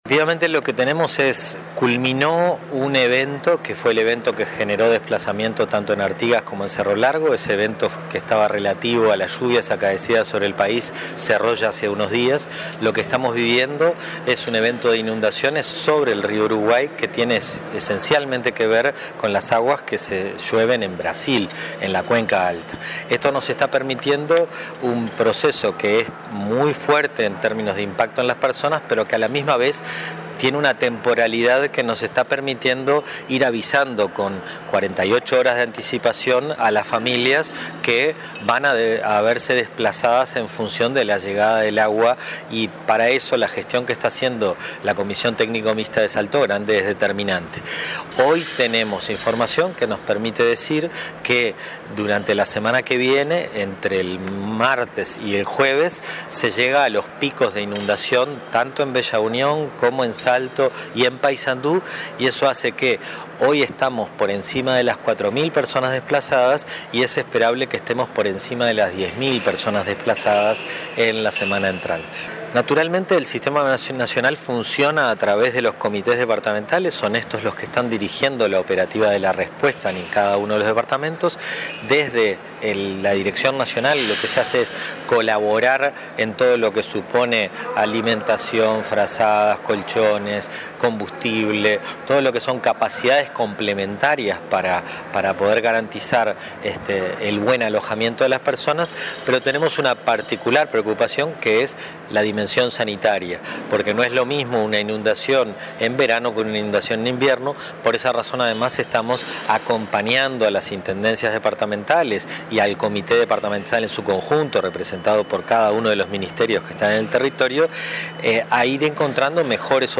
Casi 4.700 personas son las desplazadas de sus hogares por las inundaciones en el litoral, según datos del Sistema Nacional de Emergencias. Su director Fernando Traversa, sostuvo que los picos más altos de crecida del río Uruguay se darán entre el martes 13 y el jueves 15, con una previsión de desplazados de 10 mil personas. Dijo, no obstante, que el Sinae está preparado para afrontar la situación y atender a los afectados.